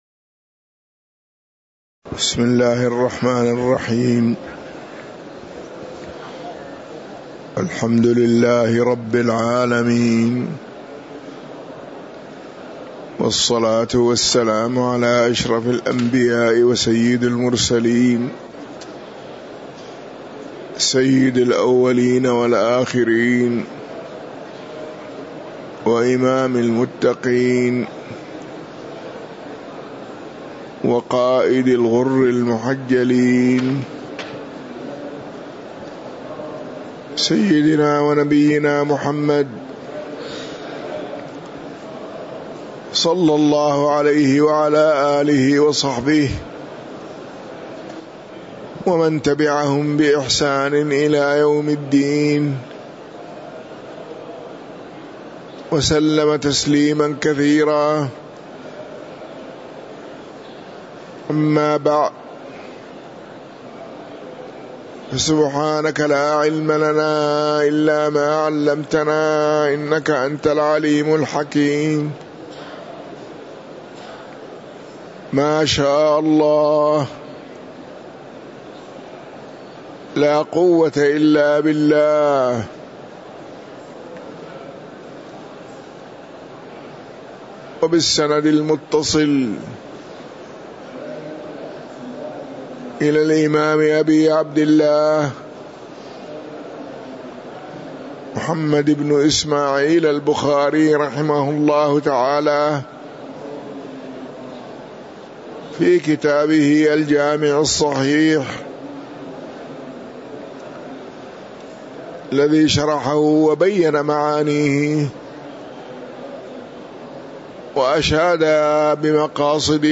تاريخ النشر ٢ ربيع الأول ١٤٤٥ هـ المكان: المسجد النبوي الشيخ